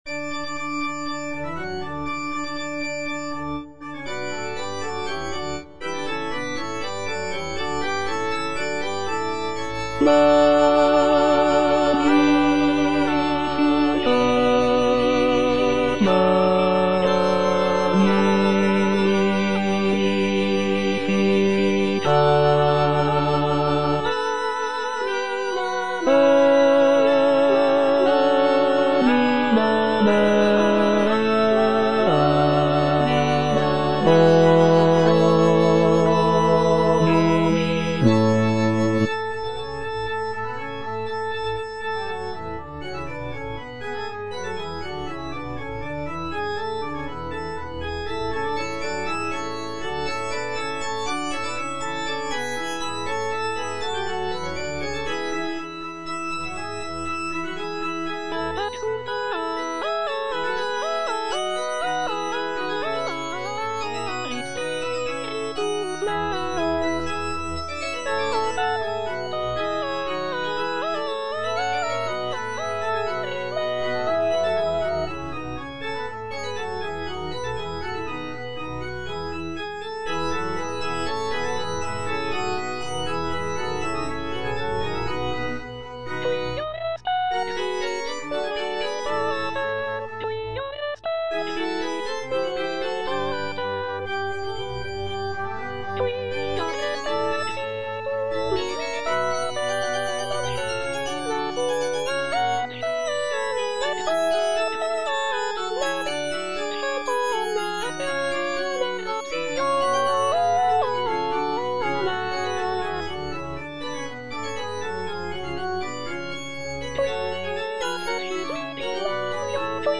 J.D. ZELENKA - MAGNIFICAT IN D MAJOR ZWV108 Magnificat - Bass (Emphasised voice and other voices) Ads stop: auto-stop
The "Magnificat ZWV108" is a choral work composed by Jan Dismas Zelenka, a Czech Baroque composer.
The composition showcases Zelenka's remarkable contrapuntal skills, with intricate vocal lines and rich harmonies.